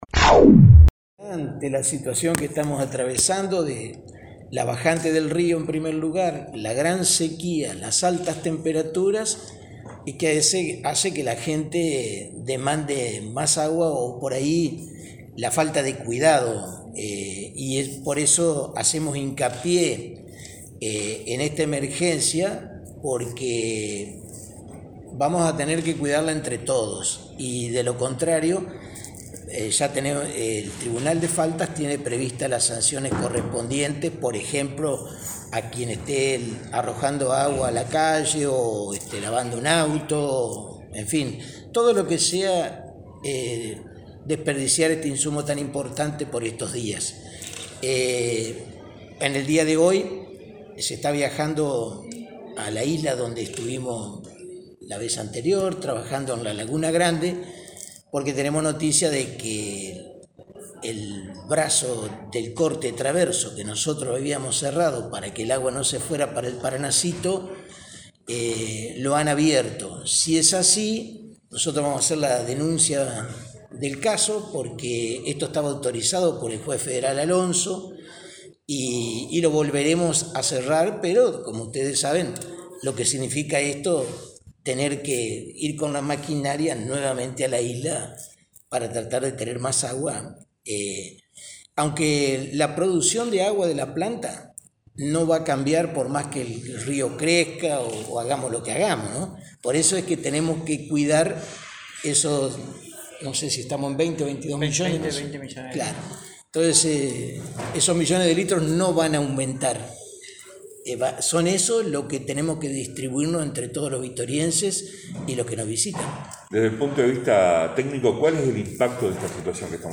En la mañana de este miércoles, a través de una conferencia de prensa, el intendente Domingo Maiocco hizo público el decreto N°1519, mediante el cual se establece la emergencia hídrica en Victoria hasta el 31 de marzo de 2022.